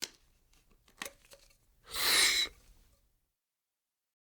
Inhaler Open and Use
SFX
yt_6FLSOEZfl2Q_inhaler_open_and_use.mp3